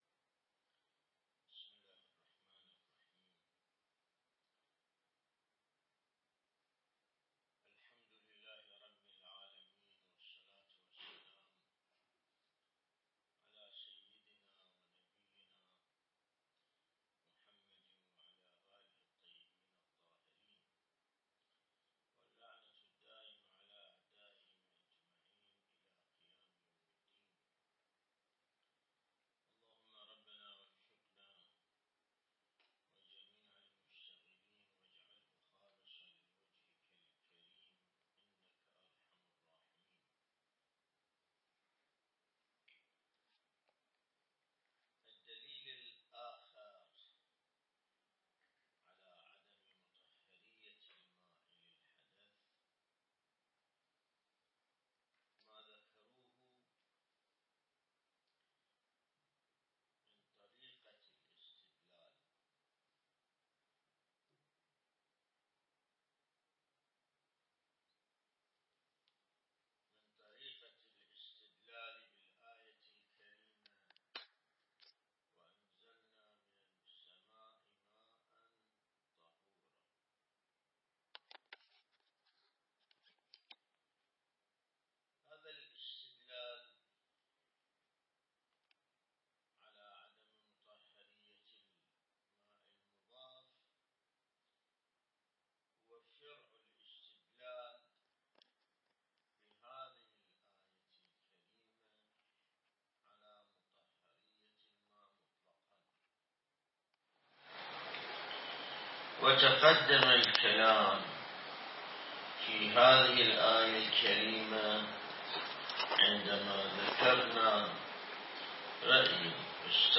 الدرس الاستدلالي شرح بحث الطهارة من كتاب العروة الوثقى لسماحة آية الله السيد ياسين الموسوي(دام ظله)